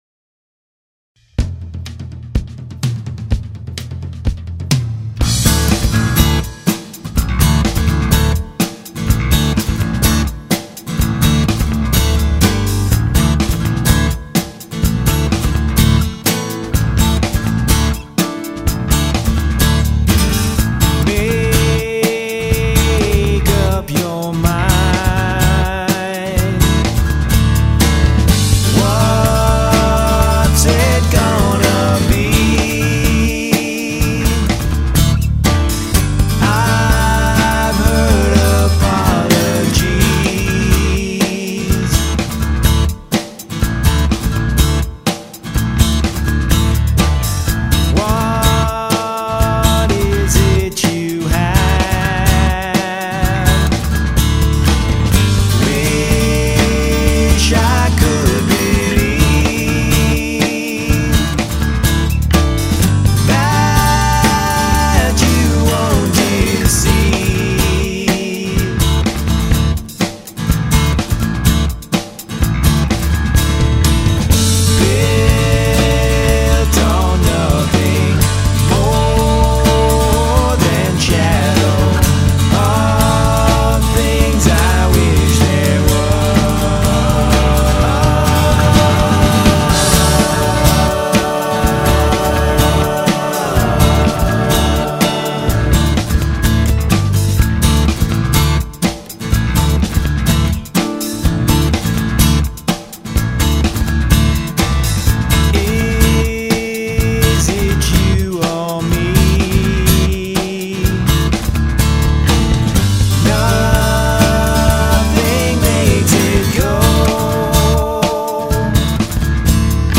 Singers:
and background vocals on "Lots To Do" and "